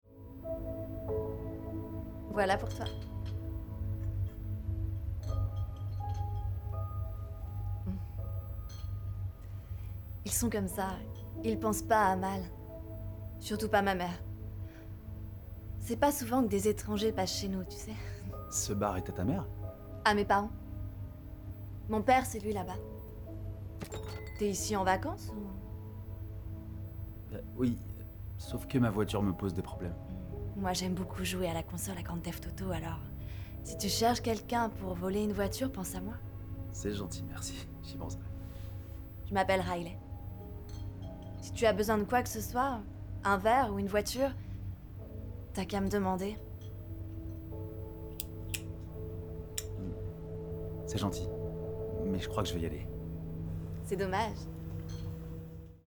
DOUBLAGE SERVEUSE BAR